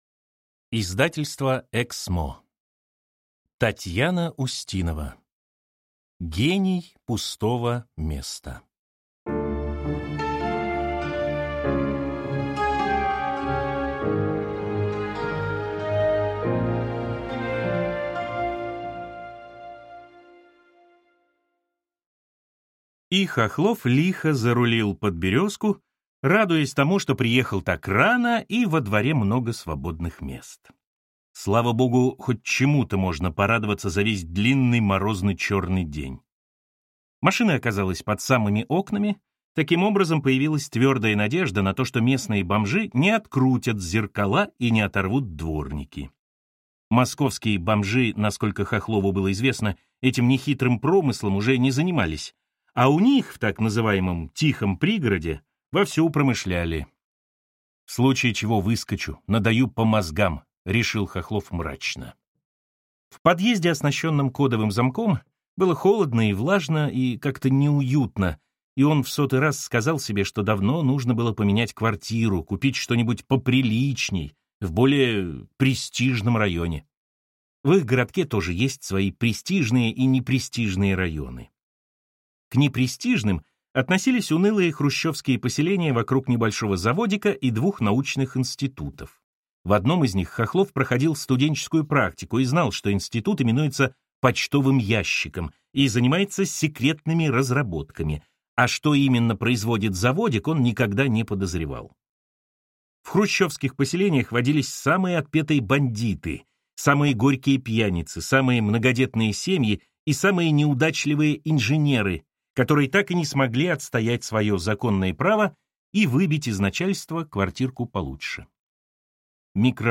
Аудиокнига Гений пустого места | Библиотека аудиокниг